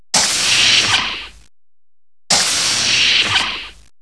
Lift Doors
liftdoors.wav